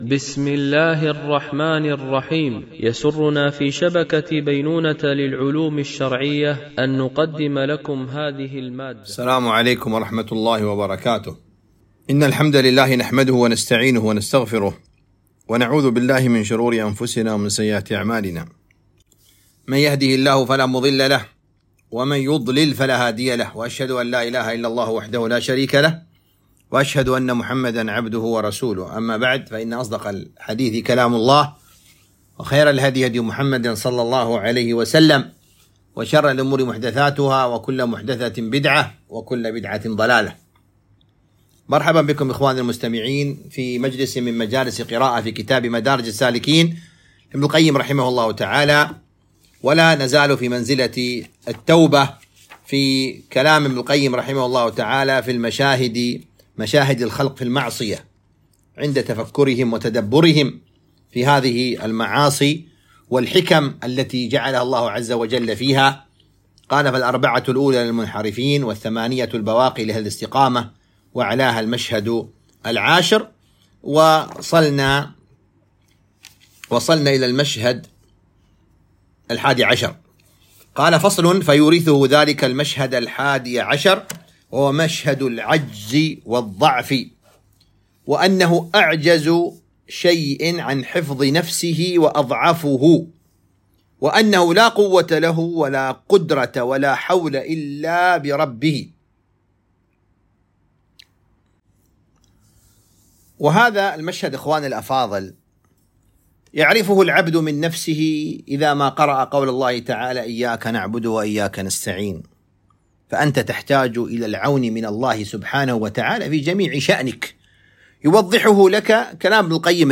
قراءة من كتاب مدارج السالكين - الدرس 45